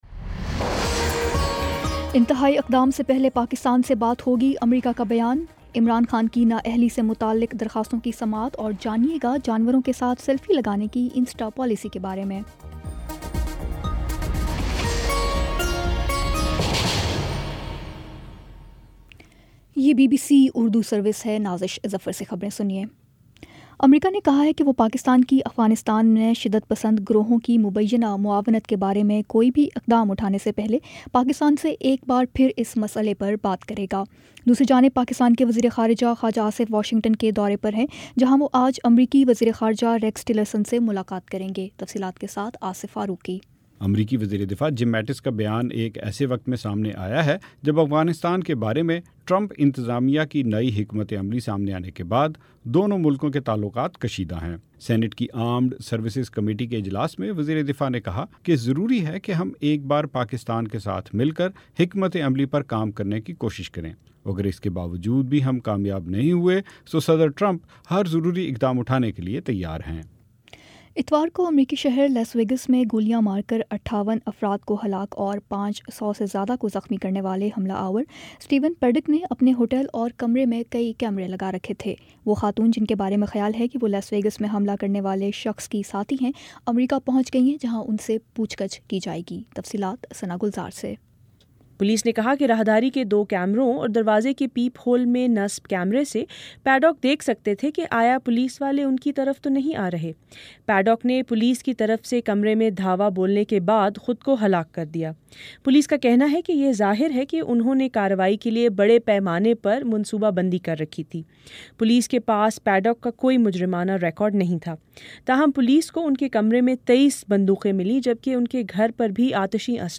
اکتوبر 04 : شام چھ بجے کا نیوز بُلیٹن